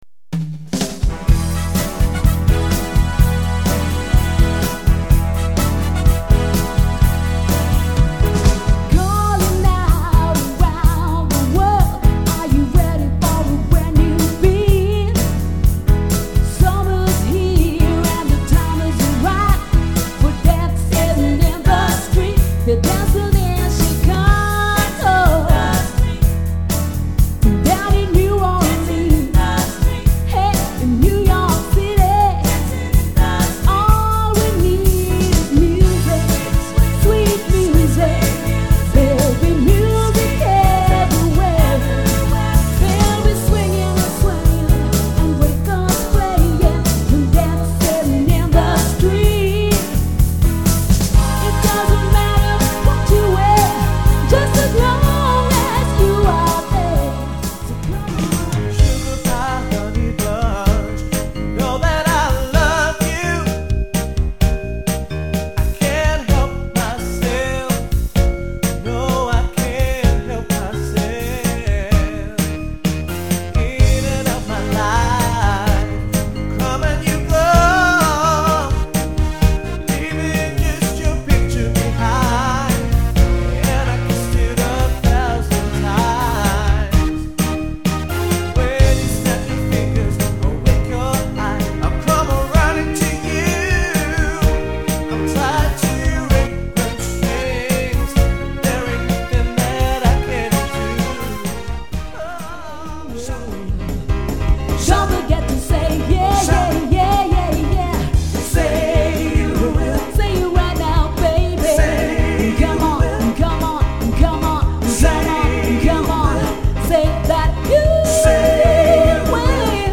4 MINUTE AUDIO DEMO MP3